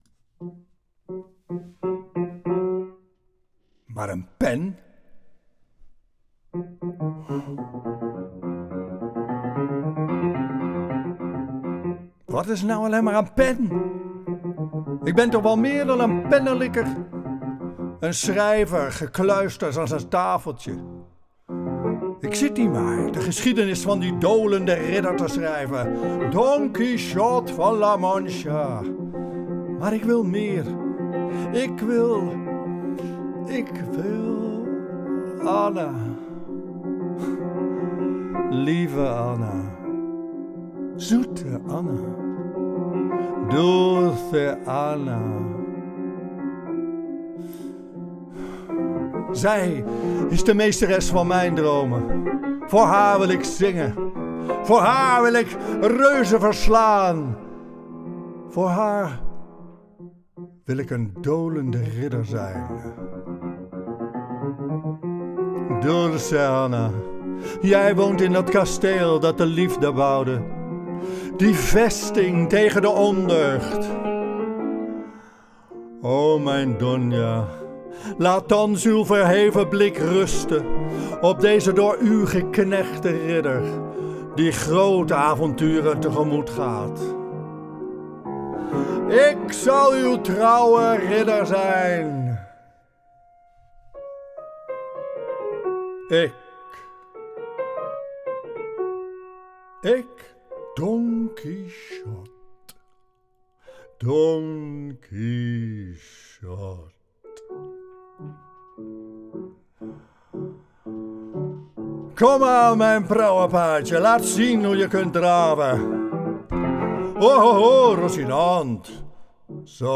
‘De Man die Don Quichot werd’ Theaterconcert over het leven van de schrijver van Don Quichot: Miguel Cervantes. Met liederen en pianostukken van o.a. Ravel, Ibert, De Falla, Mompou, Granados en Debussy.
Serenade-Interrompue-voice.wav